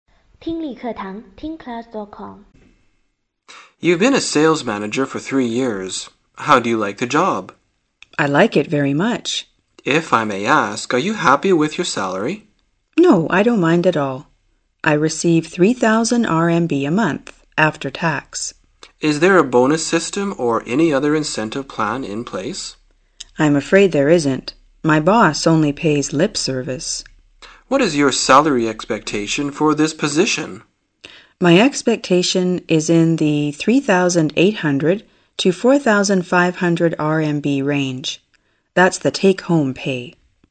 询问工资满意程度英语对话-锐意英语口语资料库20-18